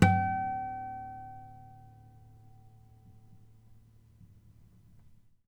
harmonic-03.wav